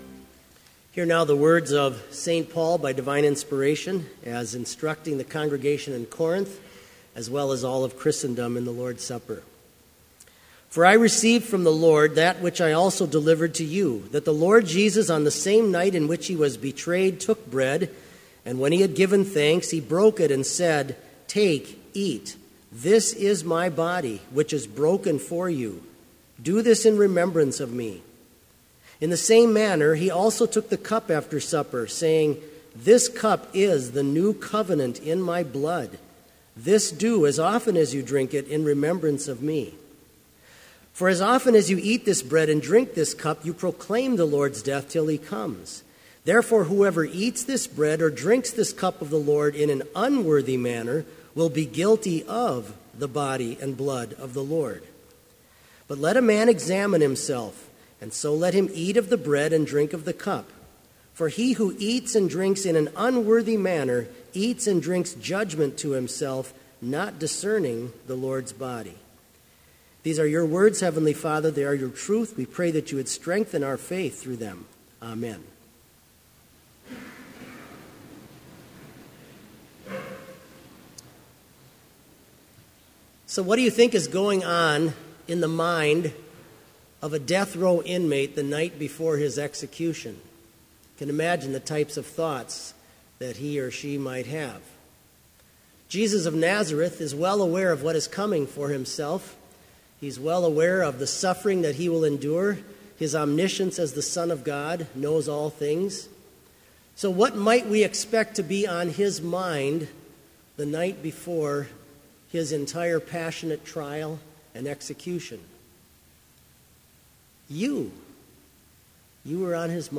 Complete service audio for Chapel - March 23, 2016